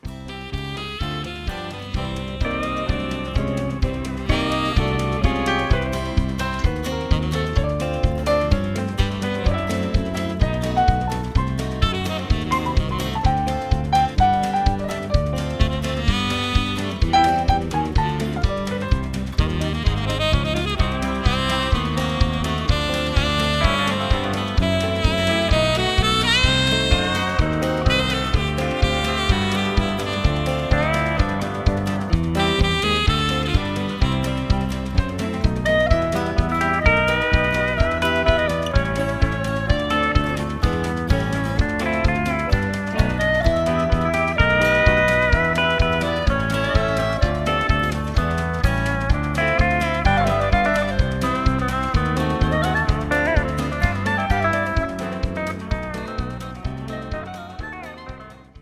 (Instrumental)
Singing Calls